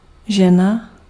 Ääntäminen
Synonyymit manželka ženská choť Ääntäminen : IPA: /ˈʒɛna/ Haettu sana löytyi näillä lähdekielillä: tšekki Käännös Ääninäyte Substantiivit 1. woman RP US UK 2. wife US UK 3. female US Suku: f .